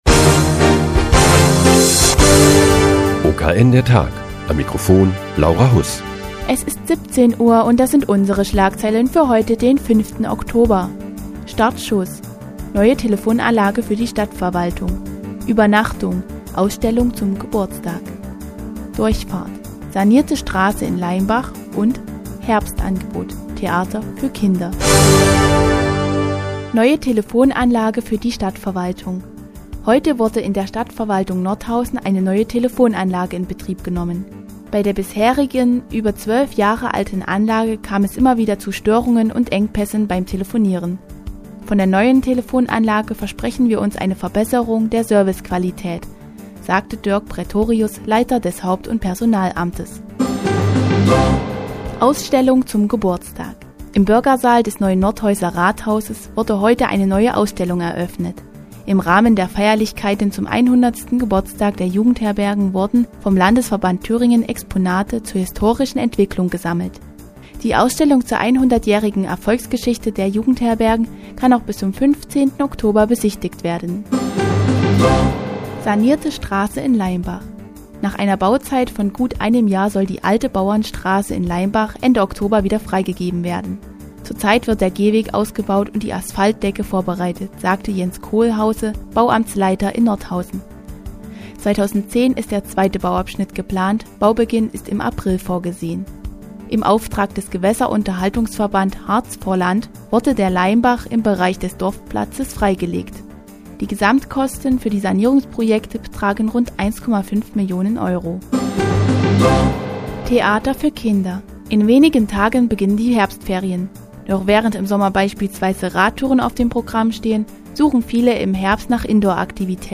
Die tägliche Nachrichtensendung des OKN ist nun auch in der nnz zu hören. Heute geht es um die sanierte Straße in Leimbach und das Kinderprogramm des Theaters unterm Dach.